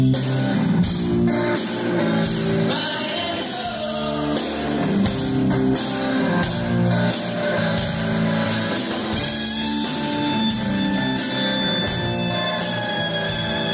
For the second straight year, the festival began with a special concert inside of the Emporia Granada Theatre Friday night.